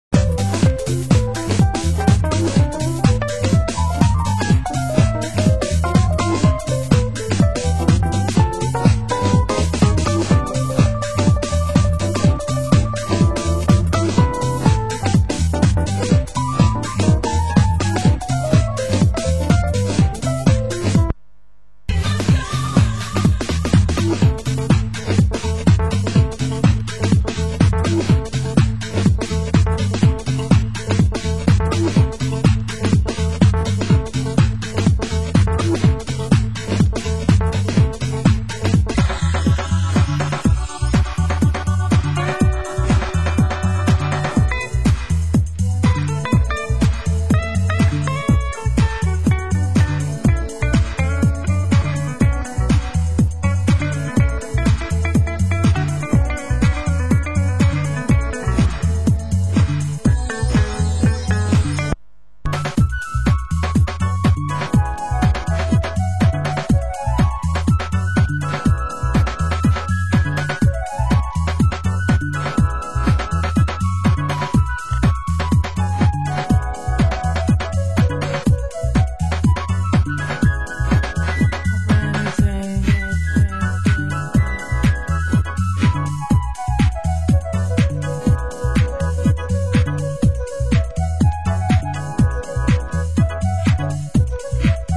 Genre: US House